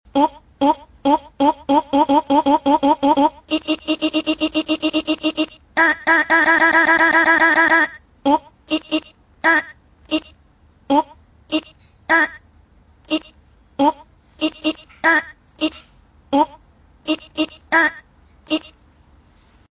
Cat Sound